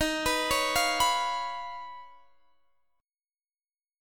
D#7sus2#5 chord